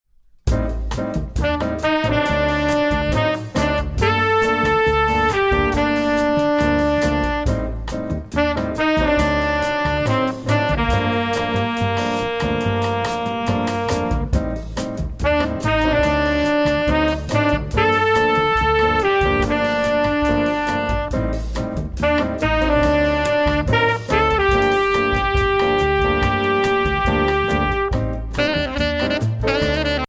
Voicing: Eb Ins